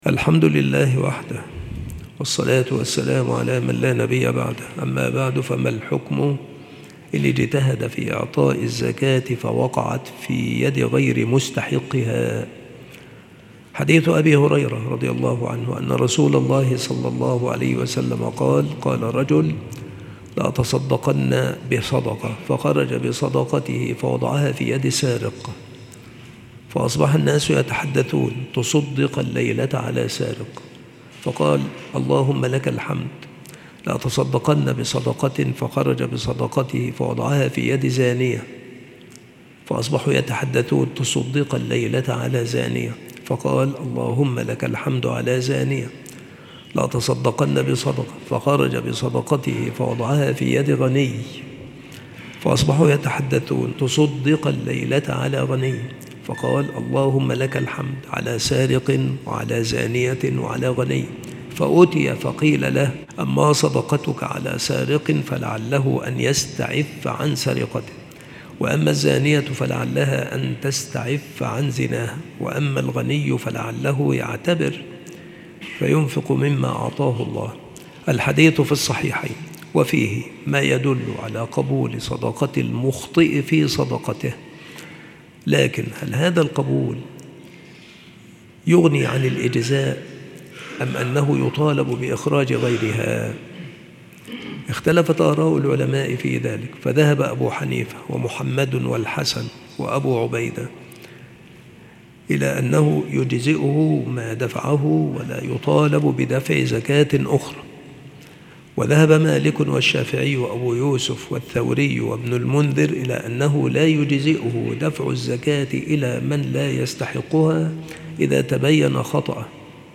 السلسلة مواعظ وتذكير
مكان إلقاء هذه المحاضرة بالمسجد الشرقي - سبك الأحد - أشمون - محافظة المنوفية - مصر